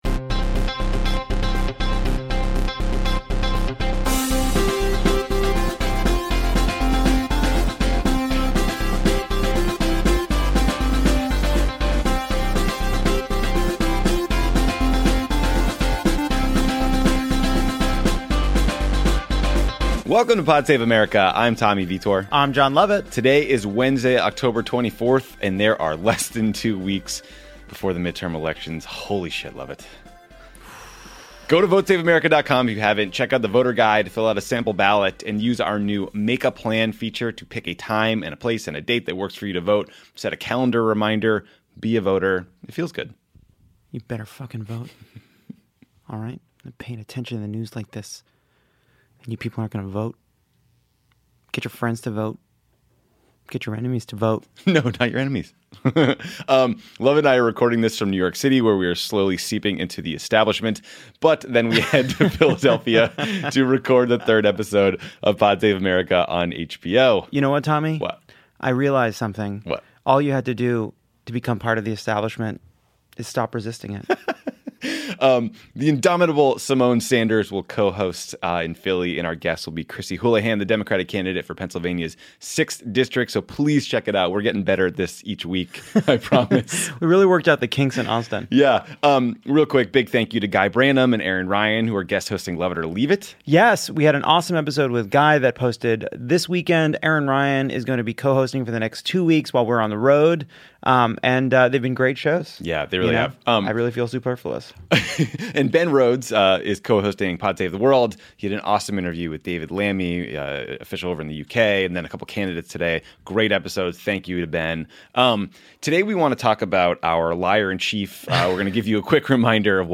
Tommy and Lovett discuss Trump's closing argument of lies and why the media needs to stop repeating them, they inexplicably have to explain why nationalism is bad, offer a health care policy update and the latest on the murder of Jamal Khashoggi. Then, Jon Favreau interviews Andrew Gillum, the Democratic candidate for governor in Florida.